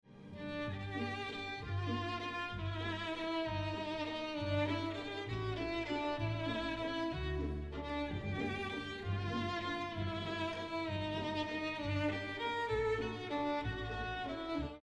During one of the recipes that require stirring, try making the work a little more fun by using motions that reflect two contrasting musical styles: legato (pronounced luh-GAHT-toh) and staccato (pronounced stah-CAHT-toh).
Use smooth and connected strokes while stirring, like the legato music.
Fam1-Activity_Legato.mp3